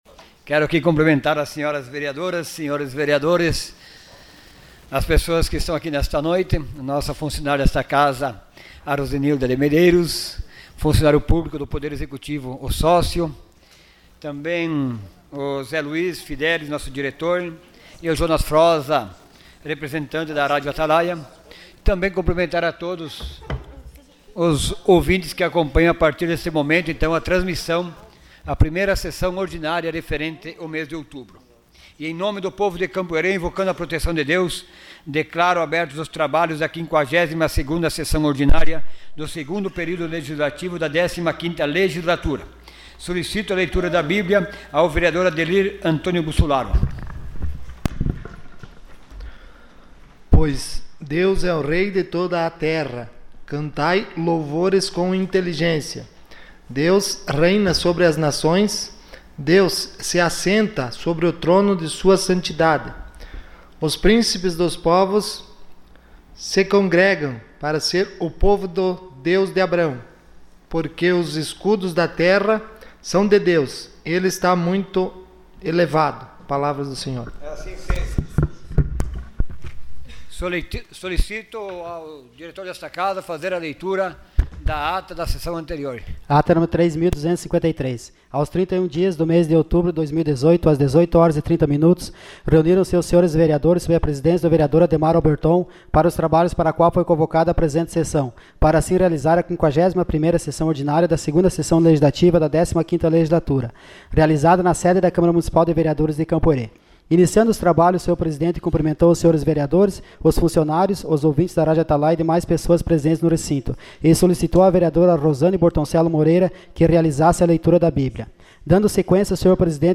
Sessão Ordinária dia 05 de novembro de 2018.